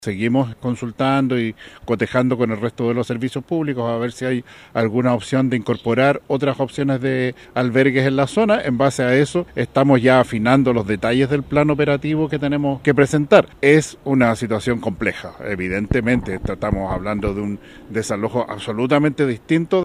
El delegado presidencial regional, Yanino Riquelme, aseguró que se mantienen las coordinaciones con otras instituciones estatales para poder incorporar más albergues, considerando que son más de 10 mil personas las que deben ser reubicadas.